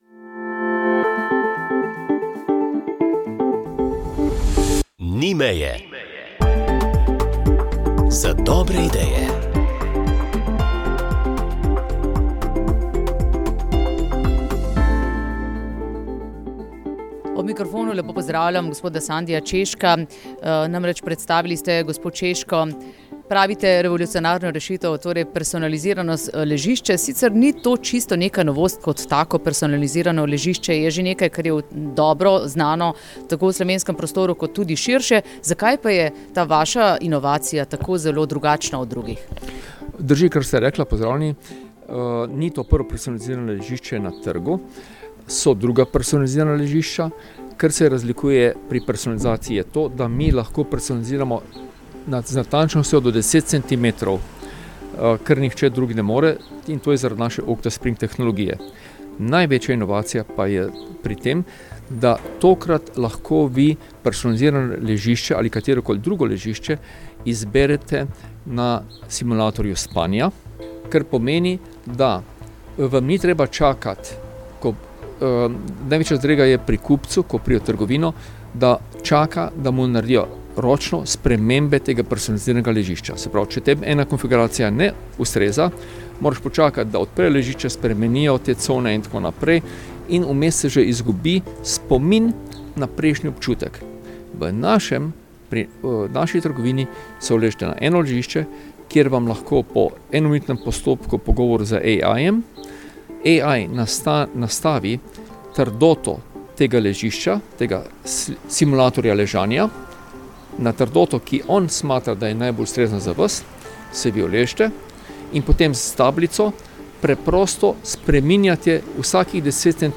Pogovor o